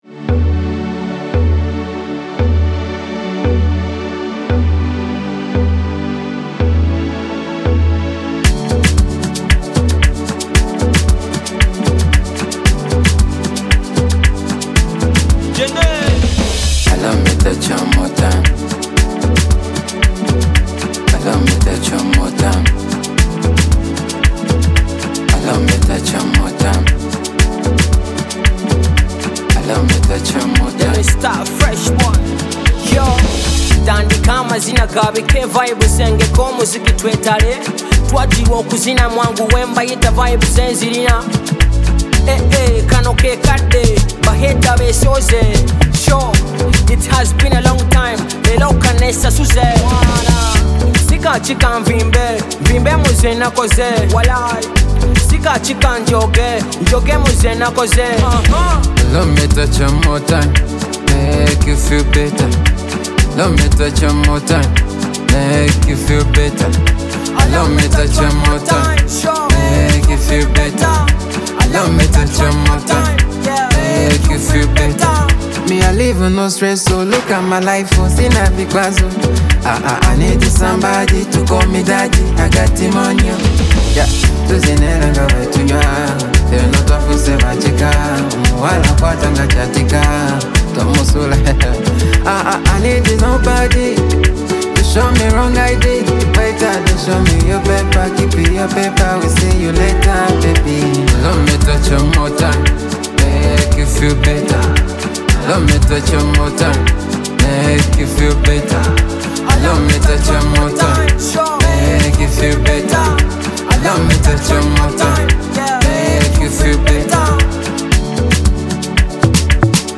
a celebrating and cheering song
Mapiano Nigerian style